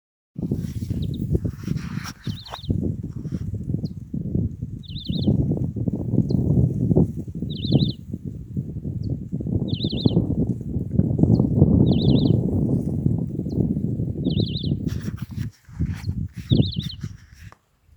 Bico-de-pimenta-chaquenho (Saltatricula multicolor)
Nome em Inglês: Many-colored Chaco Finch
País: Argentina
Localidade ou área protegida: Concordia
Condição: Selvagem
Certeza: Fotografado, Gravado Vocal